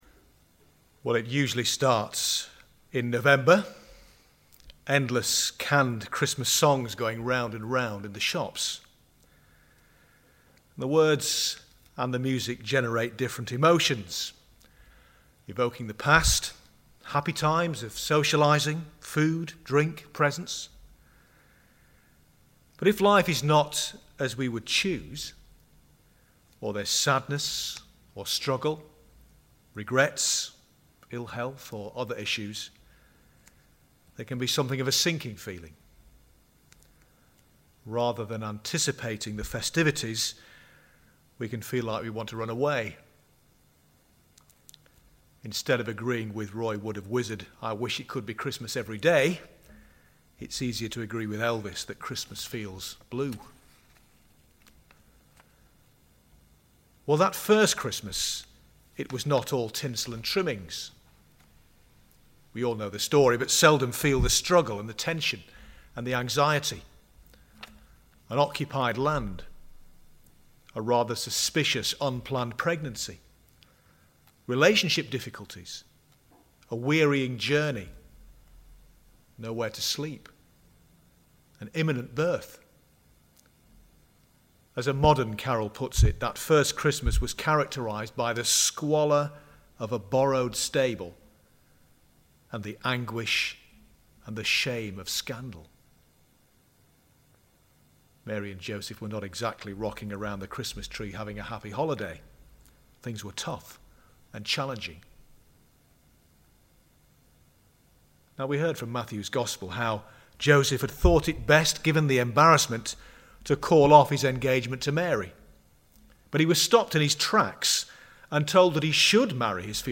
Sermon from The Longest Night Service – The sermon is less than 7 minutes, then there is a period of silence, followed by prayers starting at 9 mins 40 seconds.